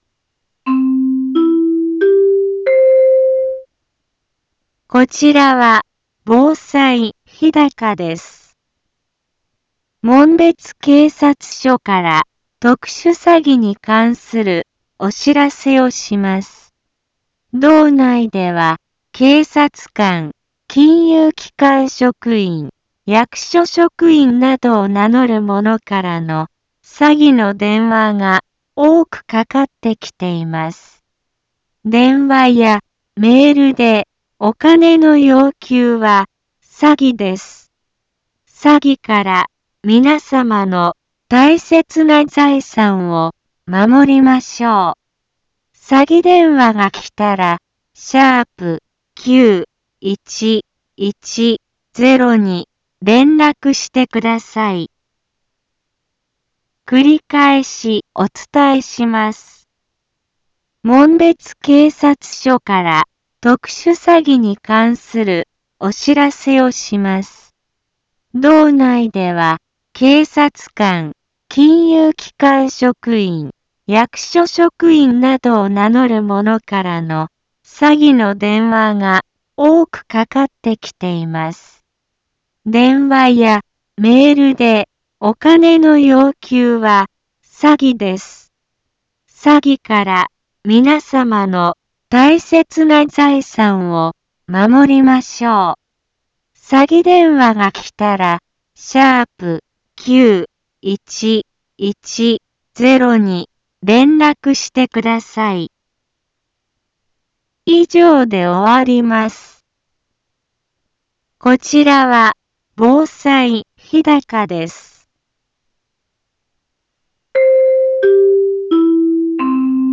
Back Home 一般放送情報 音声放送 再生 一般放送情報 登録日時：2022-10-11 10:04:13 タイトル：特殊詐欺被害防止に関するお知らせ インフォメーション：こちらは防災日高です。 門別警察署から特殊詐欺に関するお知らせをします。